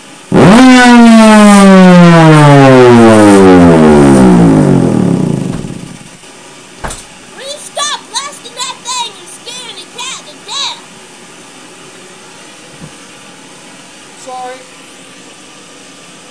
Nuclear Air Raid Siren
tbolt0001_with_ blower.wav